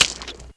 splash3.wav